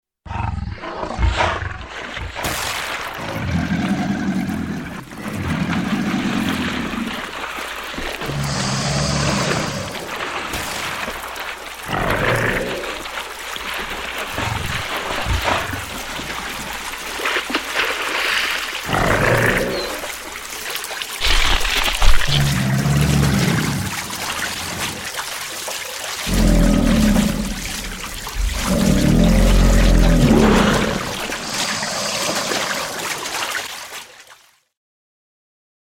Шум крокодила, плещущегося в дикой природе болота